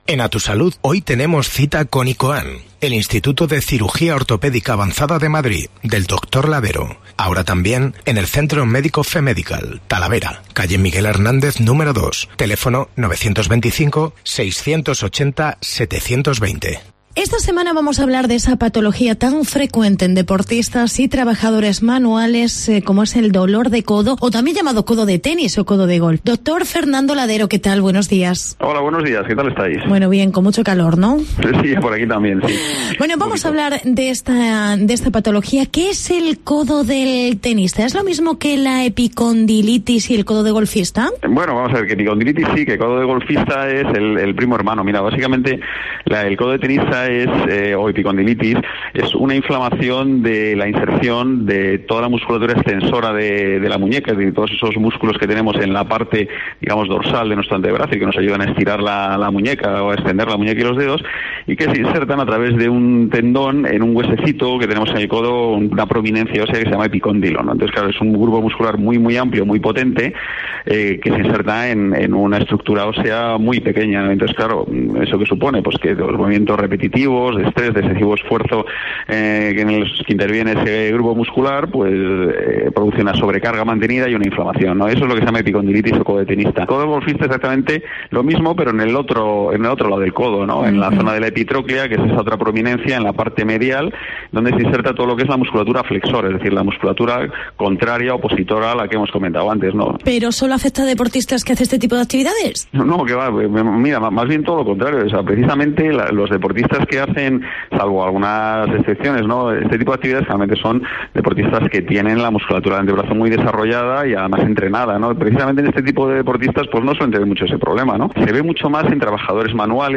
Codo de tenis y codo de golf. Tratamiento. Entrevista